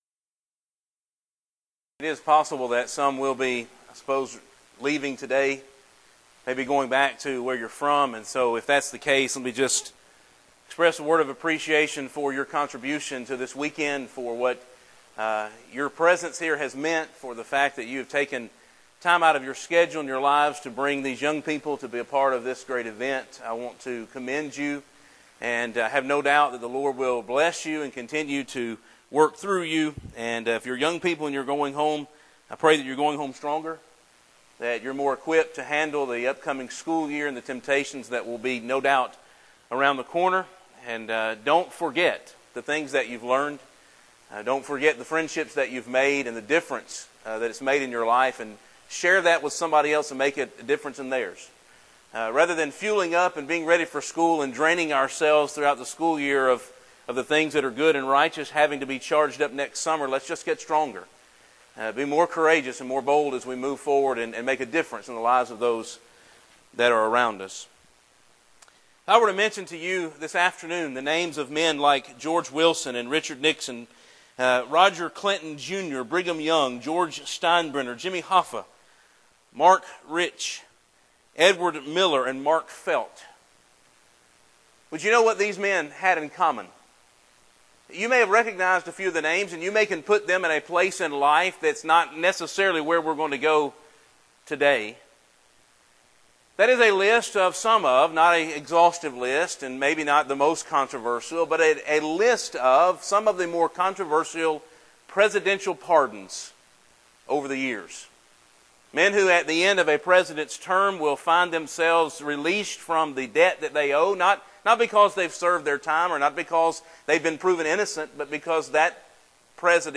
Event: Discipleship U 2016
If you would like to order audio or video copies of this lecture, please contact our office and reference asset: 2016DiscipleshipU03